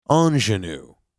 By the way, the word “ingenue” is pronounced like this: on’-zhen-oo.
pronunciation-ingenue.mp3